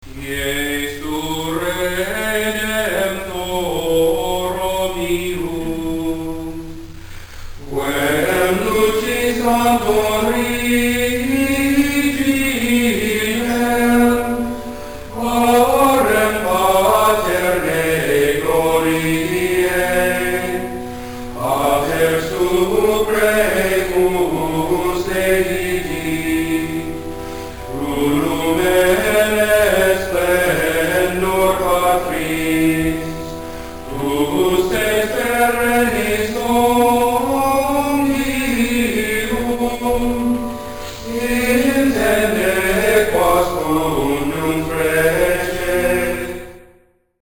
Tags: Gregorian Chants Gregorian sounds